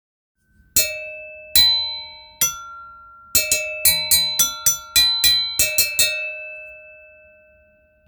ドゥンドゥンベル 3点セット
ベニン製のドゥンドゥン用の鉄ベルL・M・Sサイズの3点セットです。
素材： 鉄